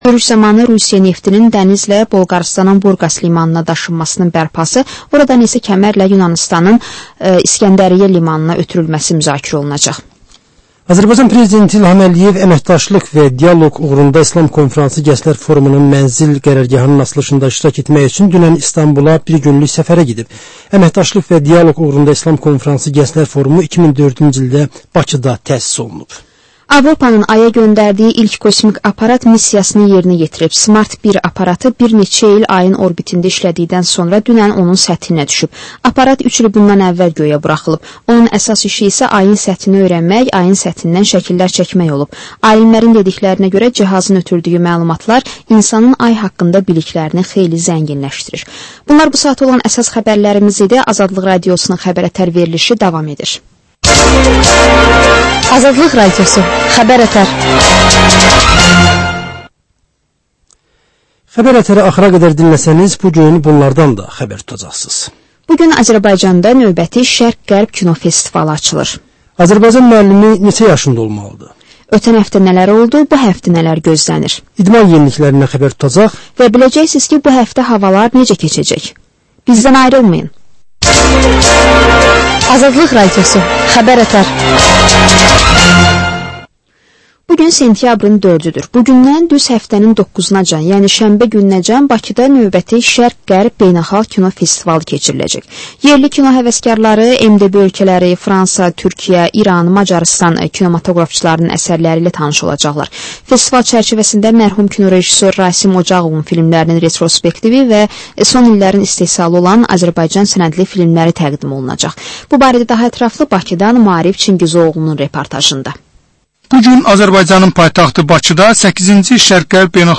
Həftənin mədəniyyər xəbərləri, reportajlar, müsahibələr